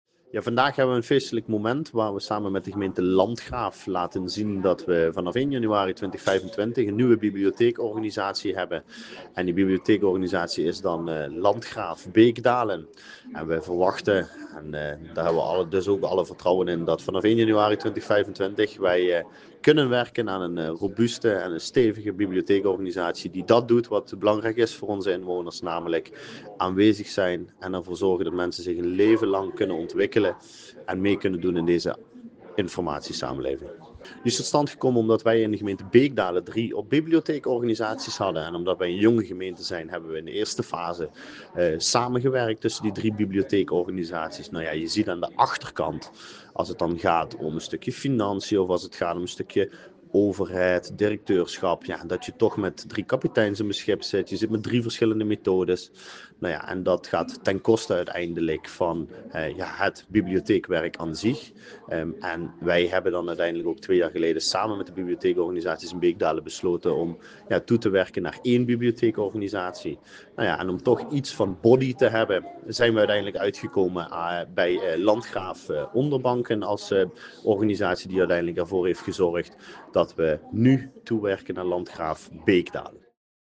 Wethouder Levin de Koster van Gemeente Beekdaelen